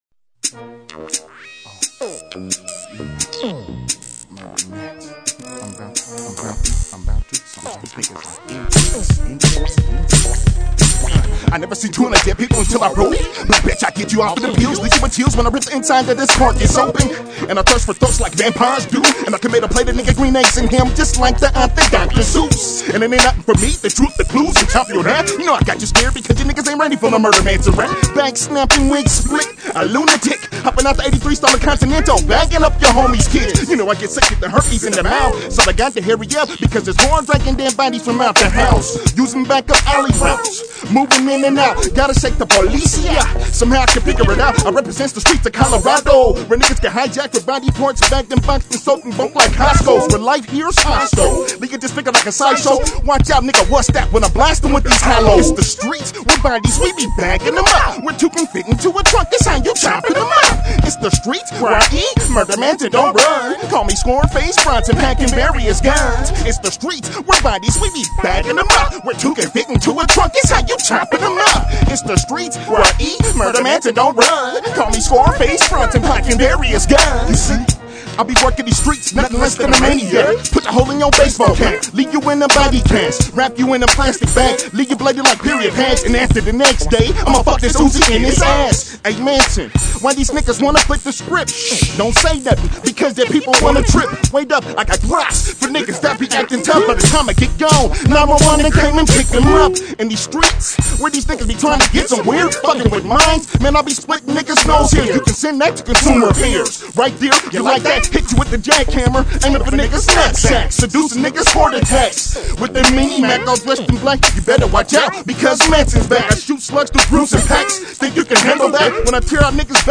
Hip-hop
Rhythm & Blues